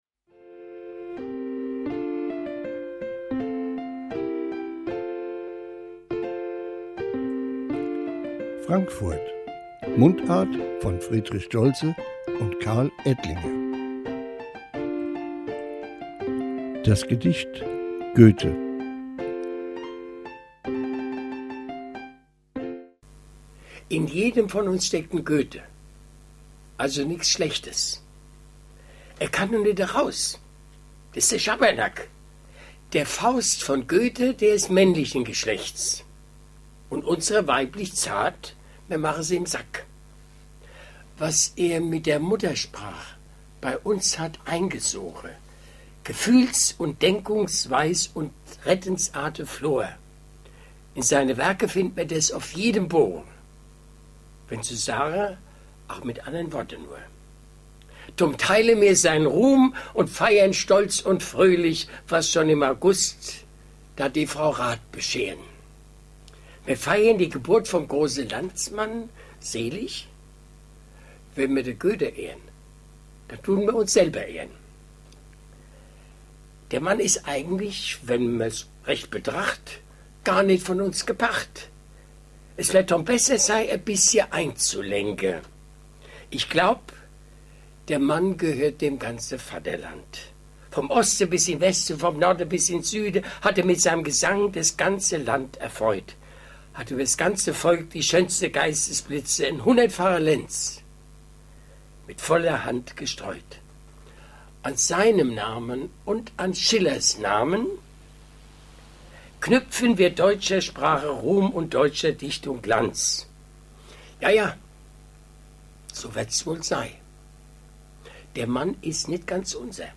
Mundart von Friedrich Stoltze "Goethe"
Gedicht: "Goethe" Friedrich Stoltze